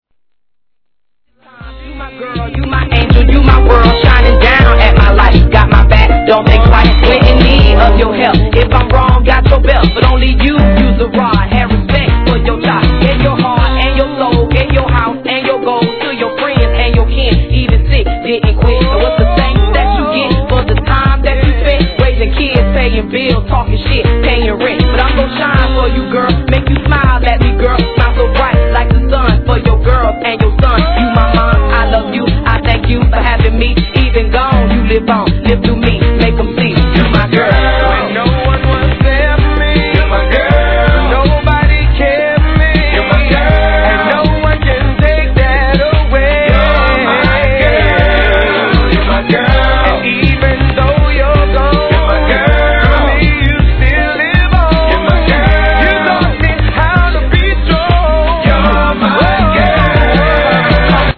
HIP HOP/R&B
'02年、哀愁漂うピアノのメロディーラインに加え男性ヴォーカルの存在によりR&B調に仕上がったミッドバウンス！！